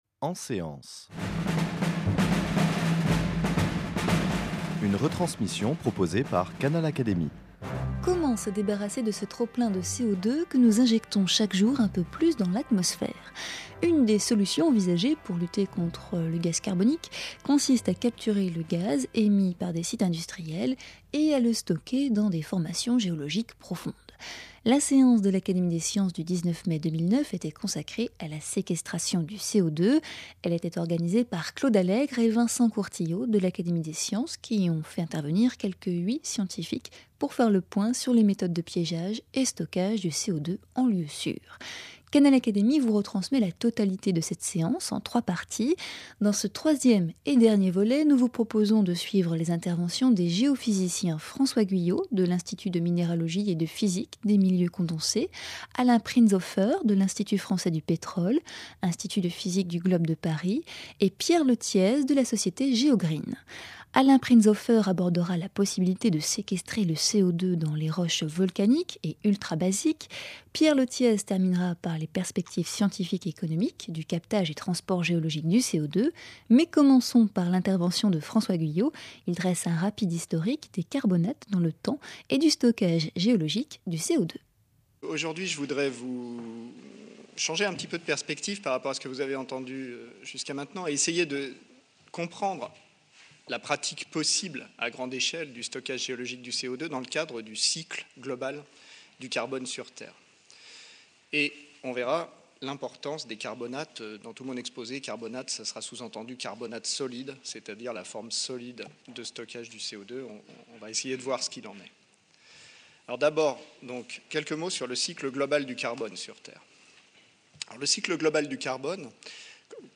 En effet, une des solutions envisagées consiste à capturer le gaz carbonique émis par des sites industriels, et à le stocker dans des formations géologiques profondes. Canal Académie vous propose d’écouter la retransmission de cette séance en trois parties.